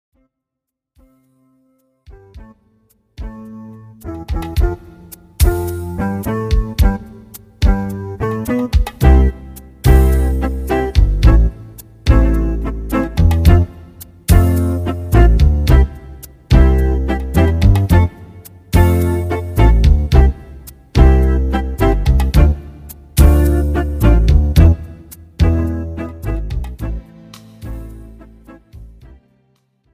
This is an instrumental backing track cover.
• Key – C♯/D♭
• Without Backing Vocals
• No Fade